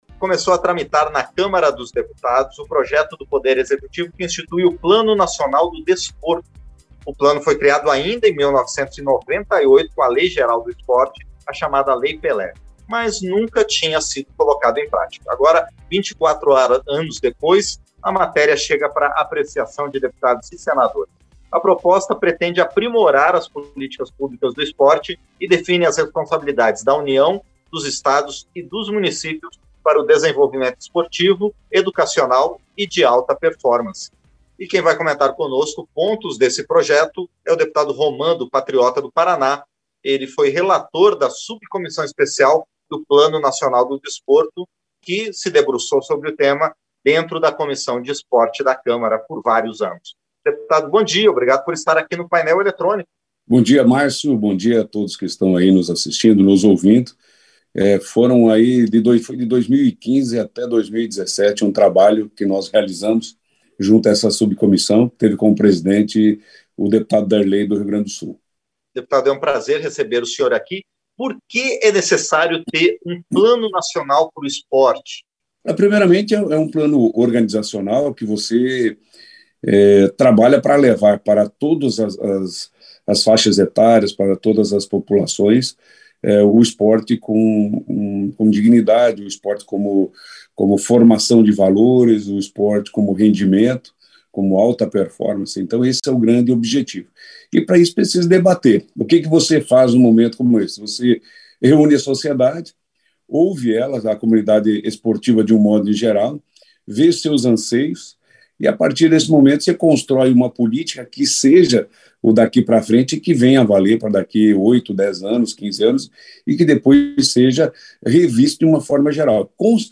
Entrevista - Dep. Roman (PATRIOTA - PR)